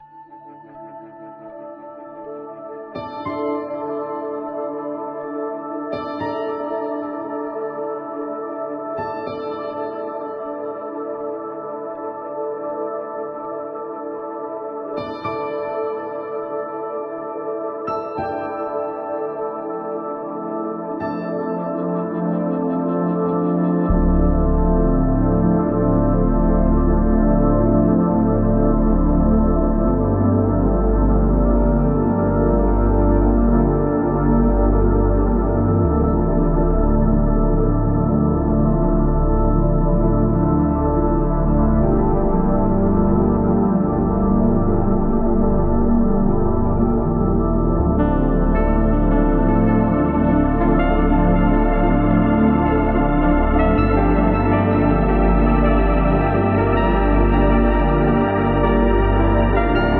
slowed + reverbed